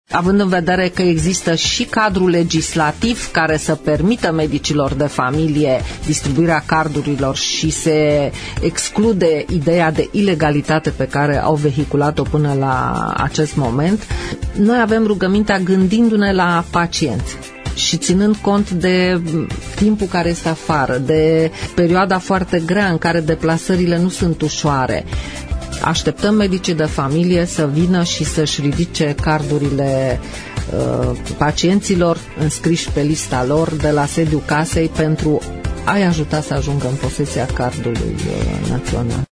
prezentă azi la emisiunea „Părerea ta”: